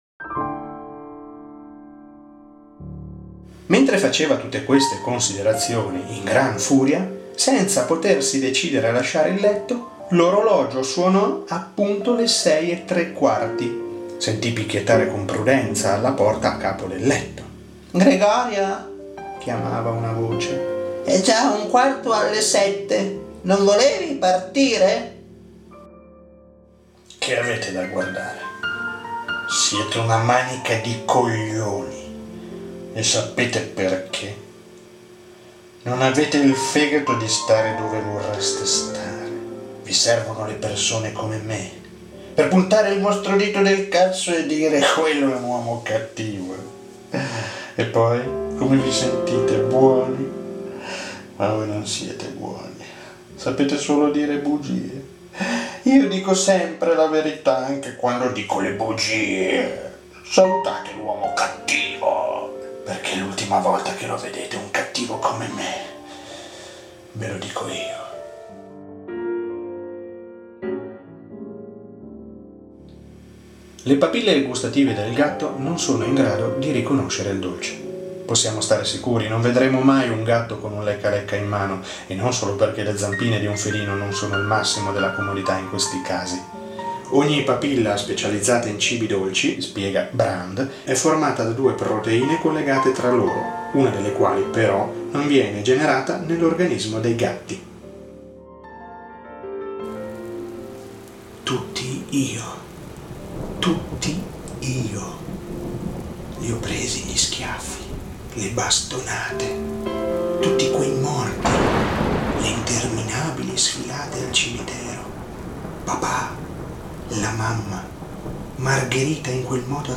voce
Demo_Voce_ITA.mp3